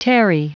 Prononciation du mot tarry en anglais (fichier audio)
Prononciation du mot : tarry